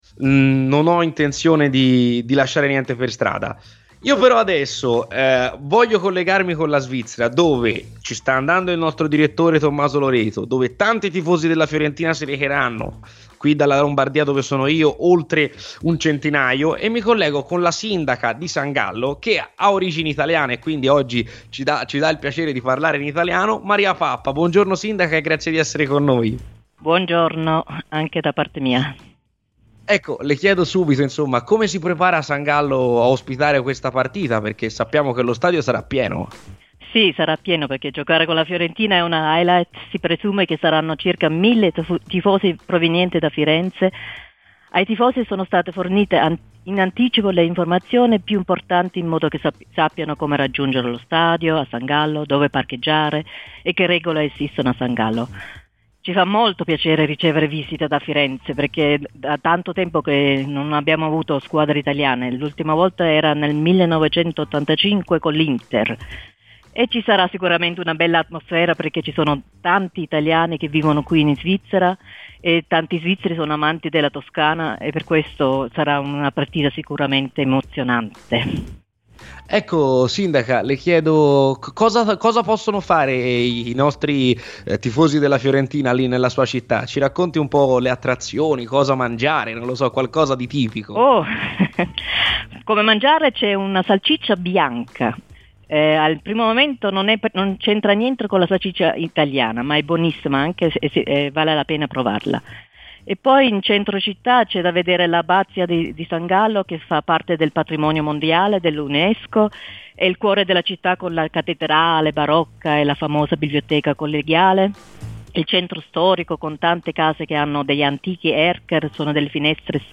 La sindaca di San Gallo di origini italiane Maria Pappa è intervenuta ai microfoni di Radio FirenzeViola durante la trasmissione "C'è Polemica" per parlare della sfida di domani tra i gigliati e la formazione svizzera.